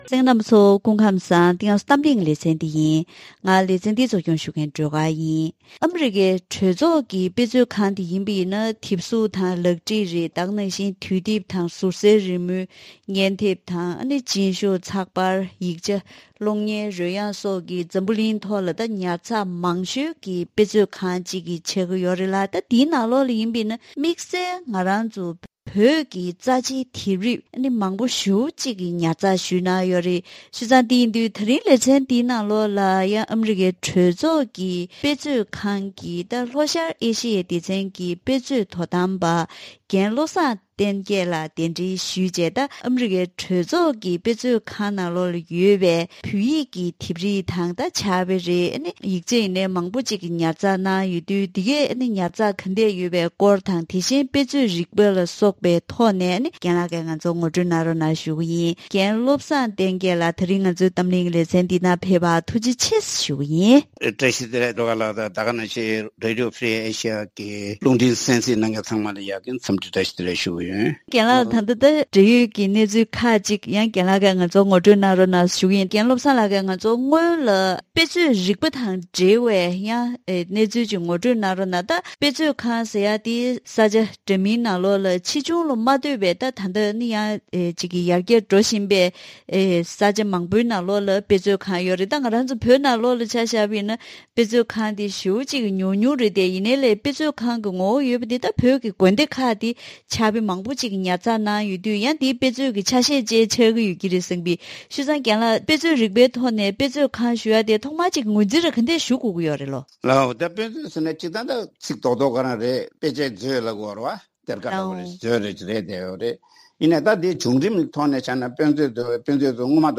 ད་རིང་གི་གཏམ་གླེང་ལེ་ཚན་ནང་ཨ་རིའི་གྲོས་ཚོགས་ཀྱི་དཔེ་མཛོད་ཁང་འདི་ནི་དེབ་རིགས་དང་ཡིག་ཆ་སོགས་འཛམ་གླིང་ཐོག་ཉར་ཚགས་མང་ཤོས་ཀྱི་དཔེ་མཛོད་ཁང་ཞིག་ཆགས་ཀྱི་ཡོད་ལ། དེའི་ནང་དམིགས་བསལ་བོད་ཀྱི་རྩ་ཆེའི་དེབ་རིགས་དང་ཕྱག་དཔེ། ཡིག་ཆ་སོགས་ཡོད་པ་ཁག་ངོ་སྤྲོད་ཞུ་རྒྱུ་དང་། དེ་བཞིན་དཔེ་མཛོད་རིག་པའི་སོགས་ཀྱི་ཐོག་ལ་འབྲེལ་ཡོད་དང་ལྷན་དུ་བཀའ་མོལ་ཞུས་པ་ཞིག་གསན་རོགས་གནང་།